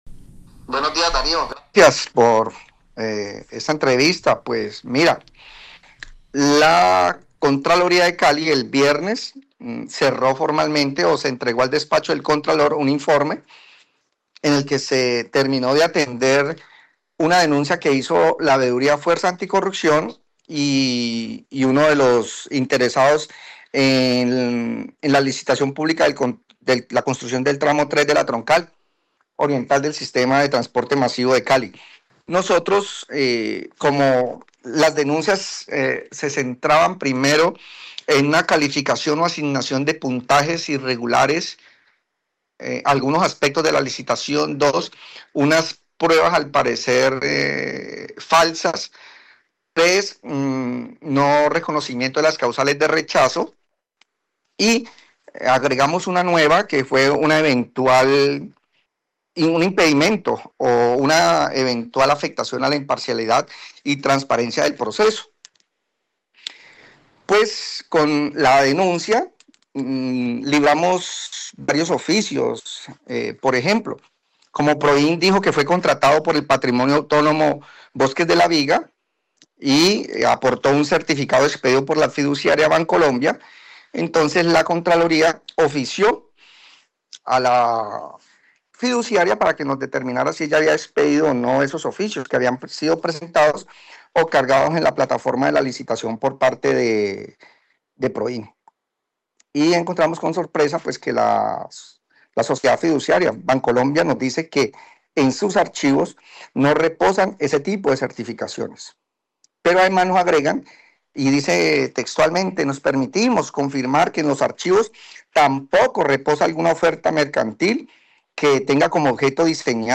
El contralor distrital de Cali, Pedro Ordoñez, en dialogo con Caracol Radio, reveló que se identificaron graves irregularidades en la adjudicación de la licitación del contrato para la construcción del tramo 3 de la troncal oriental del Sistema Integrado de Transporte Masivo - MIO, y que tiene un costo de 143 mil 282 millones de pesos.